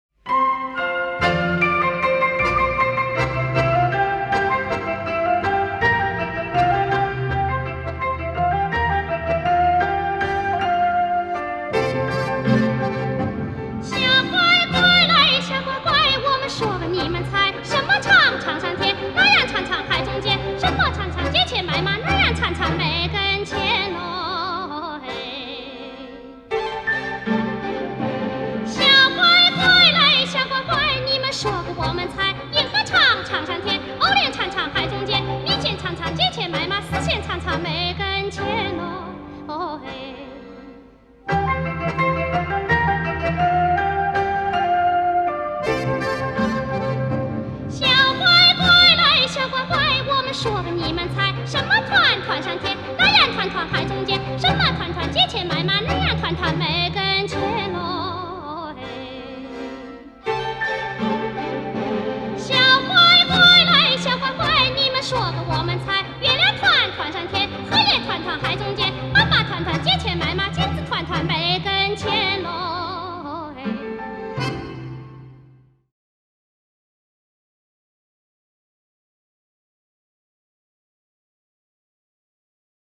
著名歌唱家合辑